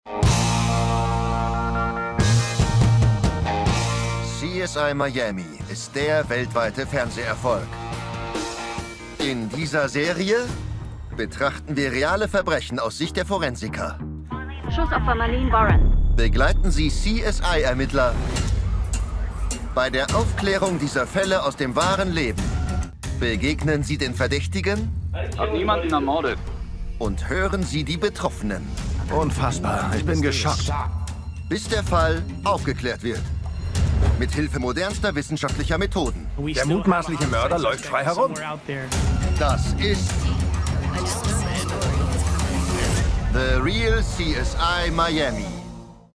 dunkel, sonor, souverän
Berlinerisch